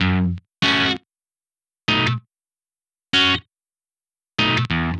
Track 14 - Guitar.wav